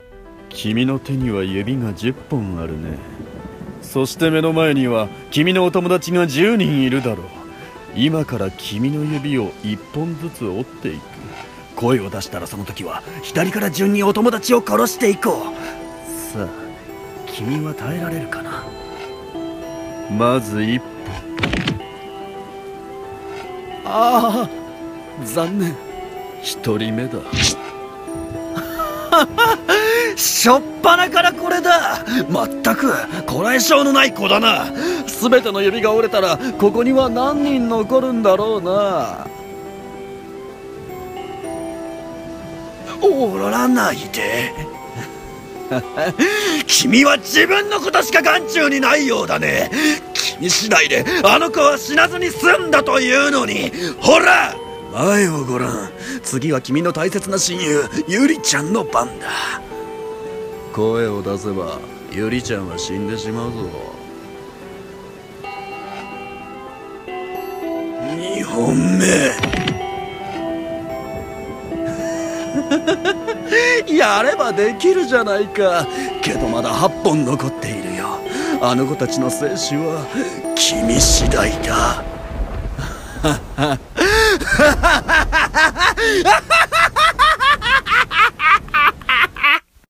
指折り殺人鬼【ホラー声劇